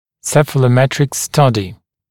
[ˌsefələˈmetrɪk ‘stʌdɪ][ˌсэфэлэˈмэтрик ‘стади]цефалометрические исследование